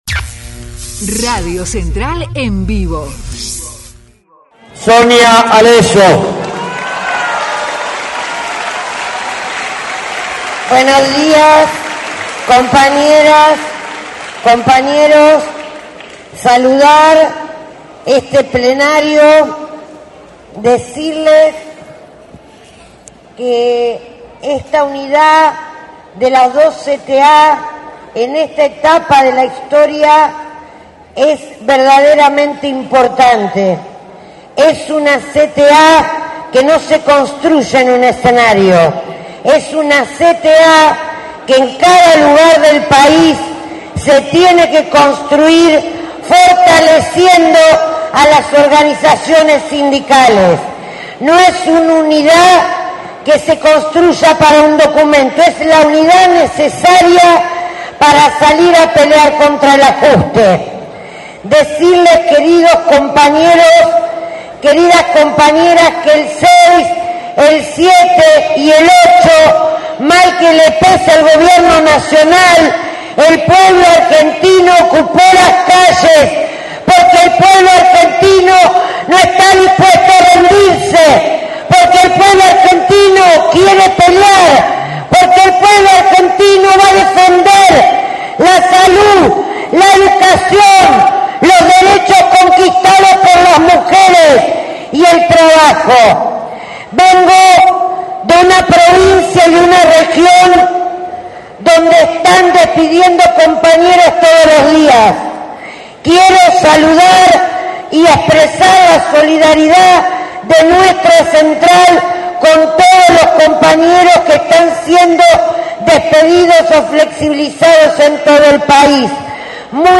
PLENARIO NACIONAL CTA UNIFICADA